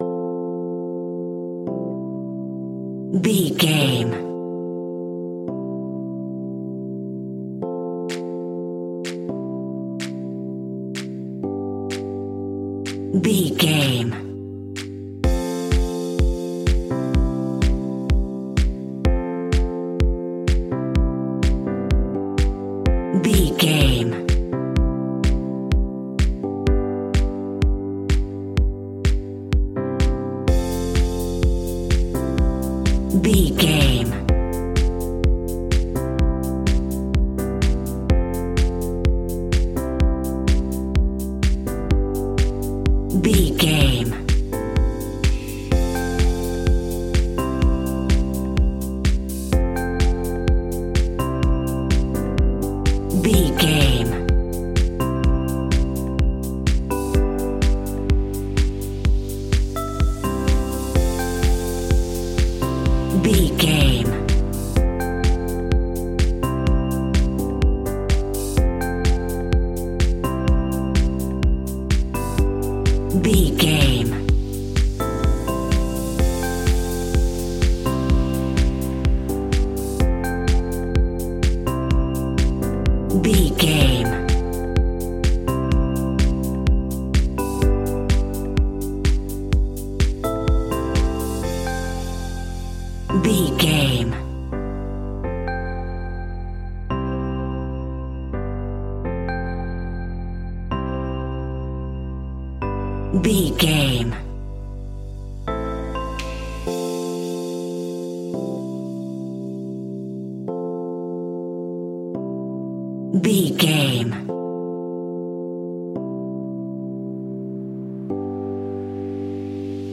Joyful Tropical House Alt .
Ionian/Major
groovy
uplifting
driving
energetic
repetitive
drums
synthesiser
drum machine
electric piano
house
electro house
synth leads
synth bass